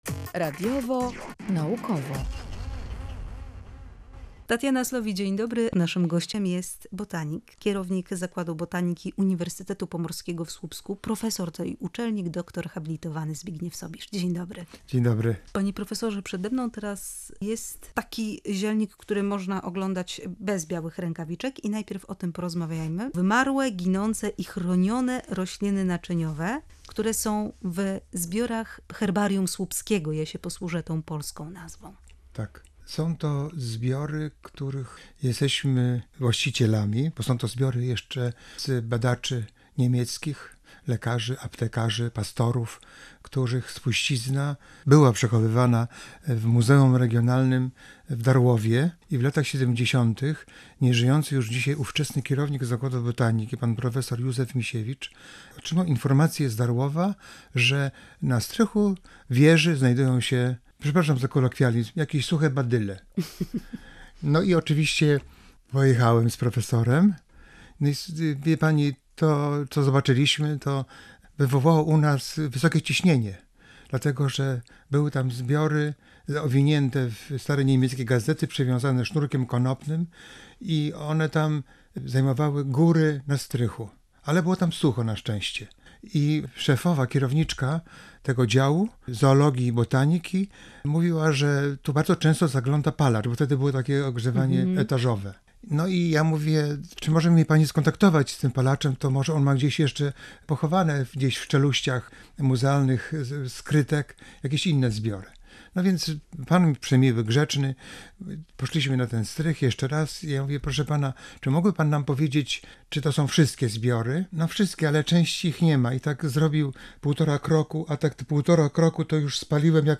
Botanik odwiedził studio Radia Gdańsk z zabytkowym, najstarszym na Pomorzu zielnikiem.